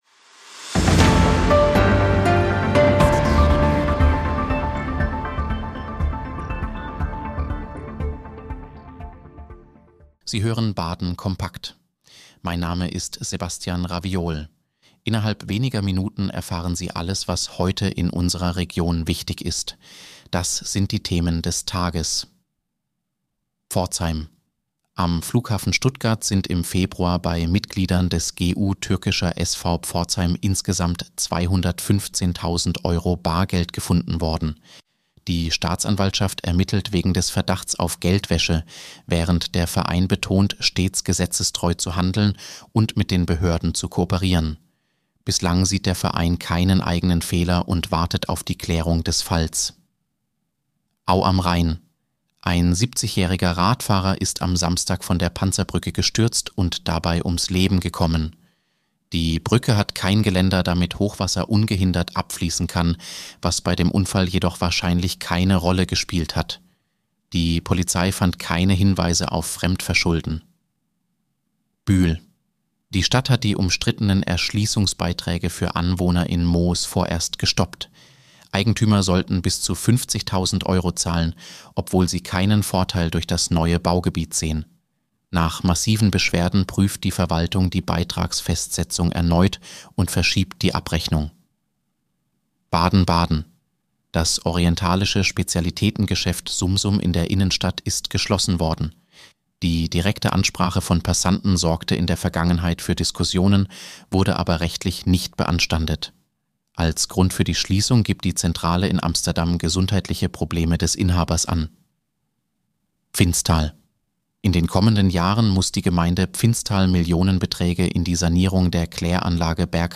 Nachrichtenüberblick: Pforzheimer Fußballverein reagiert auf Geldwäsche-Vorwürfe